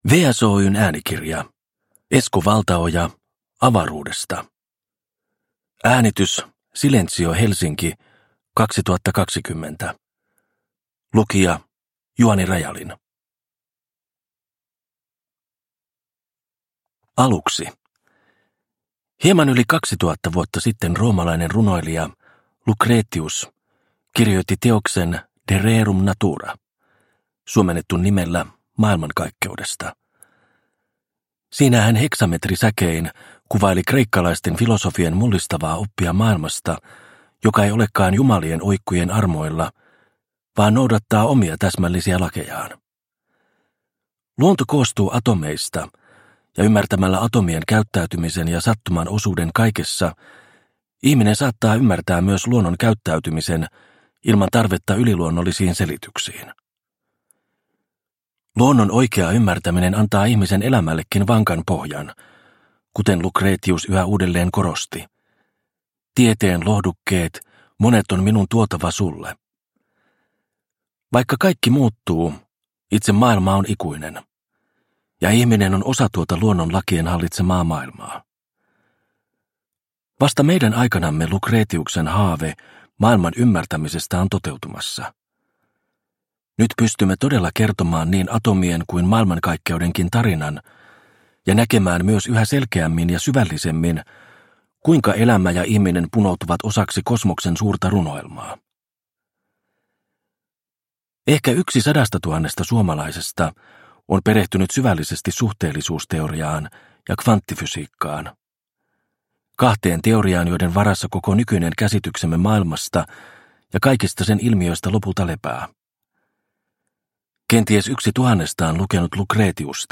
Avaruudesta – Ljudbok – Laddas ner